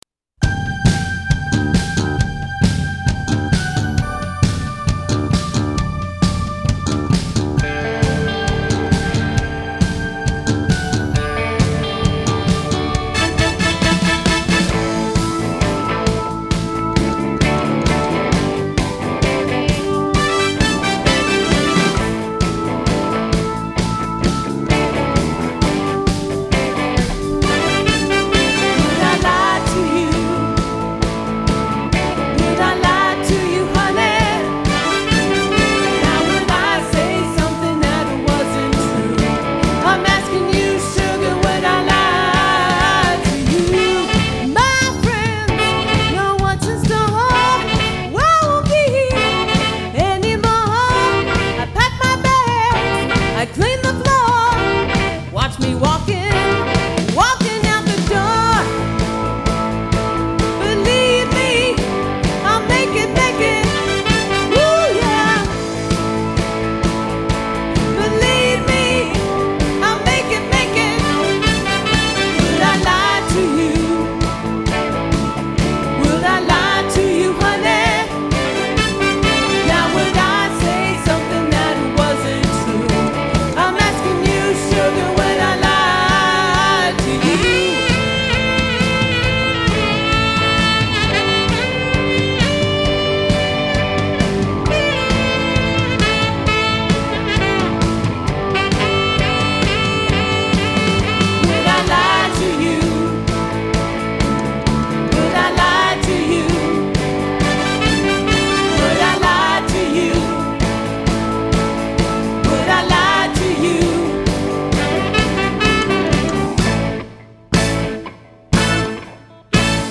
Recorded at Polar Productions Studio, Pismo Beach, CA